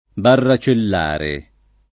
vai all'elenco alfabetico delle voci ingrandisci il carattere 100% rimpicciolisci il carattere stampa invia tramite posta elettronica codividi su Facebook barracellare [ barra © ell # re ] (meno bene baracellare [ bara © ell # re ]) agg.